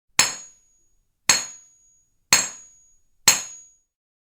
Hammer Spoon Strikes
Hammer Spoon Strikes is a free sfx sound effect available for download in MP3 format.
yt_Ue-EgKVphkE_hammer_spoon_strikes.mp3